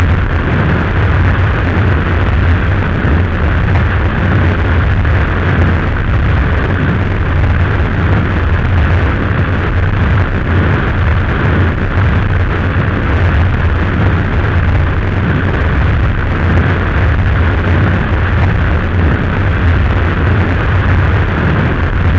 thrust4.mp3